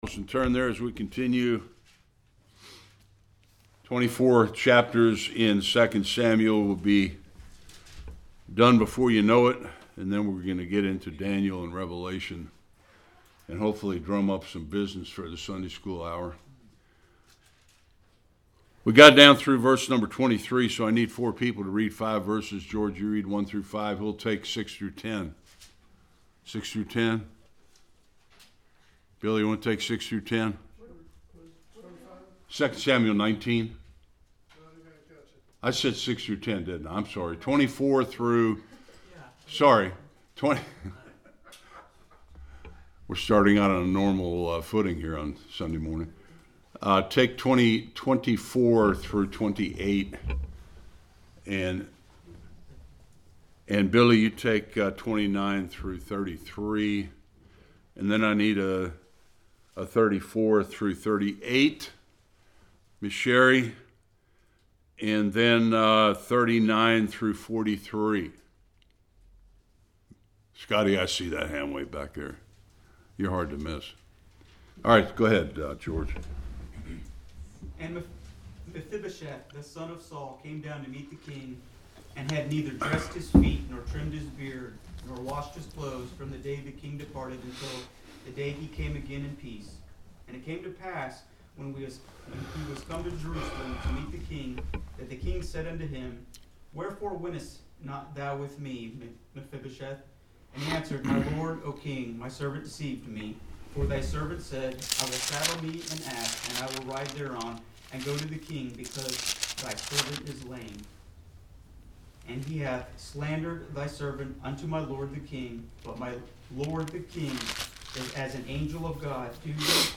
1-4 Service Type: Sunday School Another rift between Israel and Judah led by Sheba.